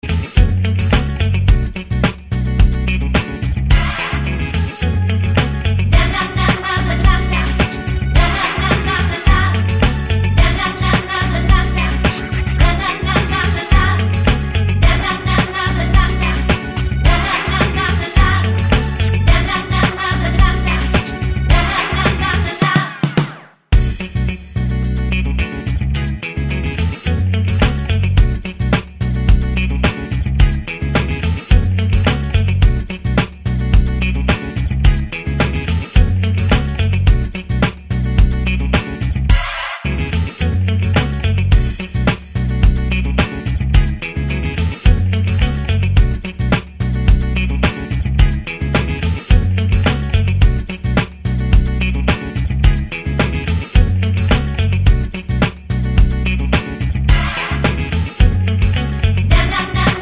no male Backing Vocals R'n'B / Hip Hop 3:38 Buy £1.50